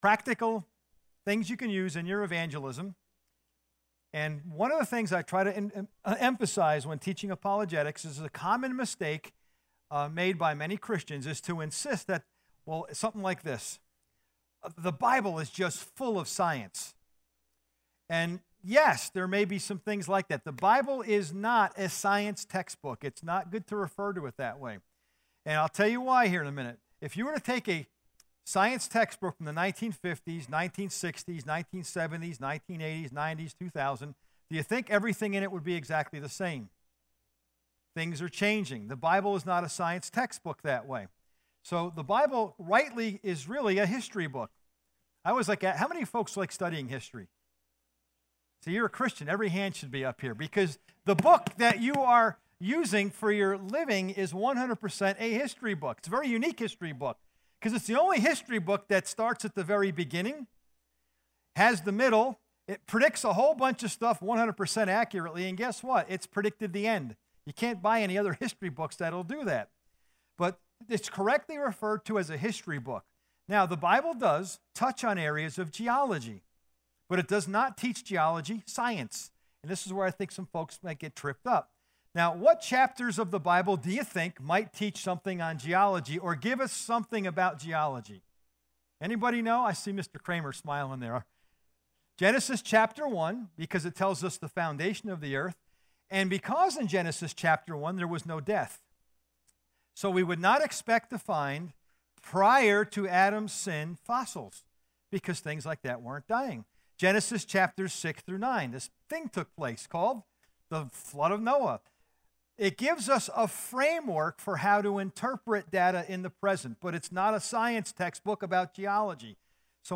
Guest Speaker
Current Sermon